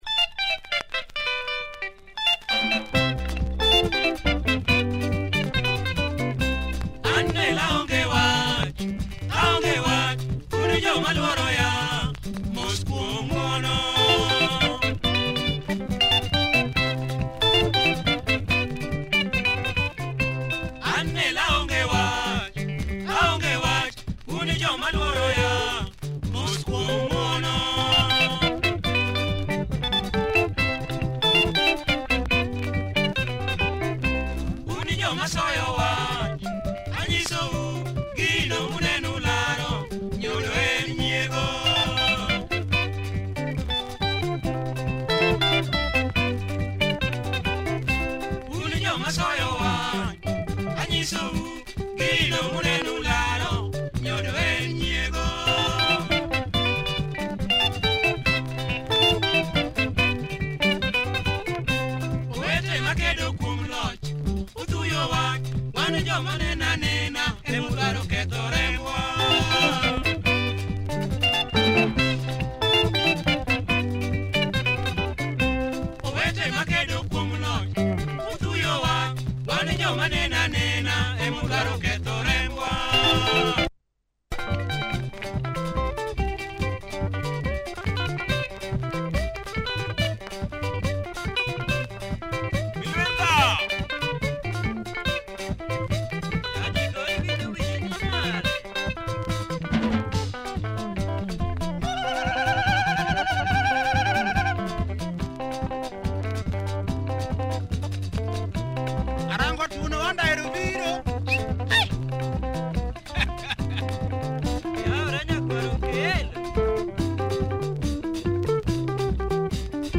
Super Luo bumper